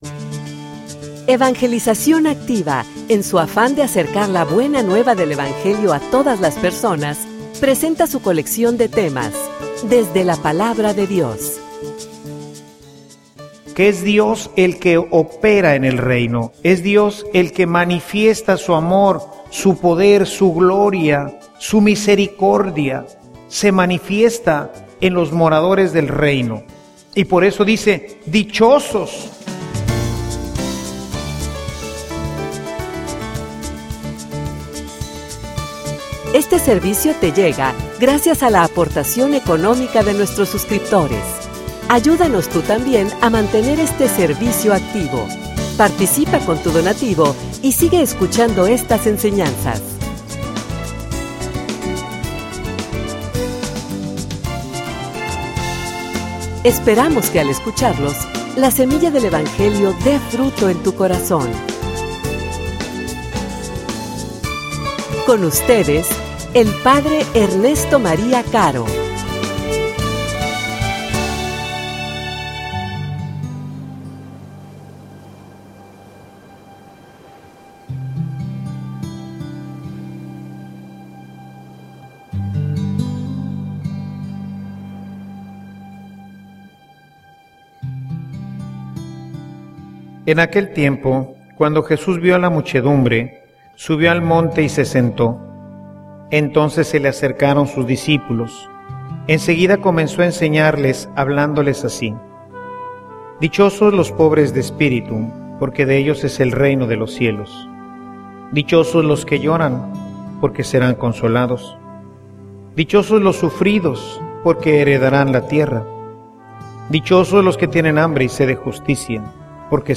homilia_Dichosos_los_pobres_de_espiritu.mp3